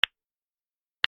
/ G｜音を出すもの / G-50 その他 手をたたく　体
指を鳴らす
『パキ』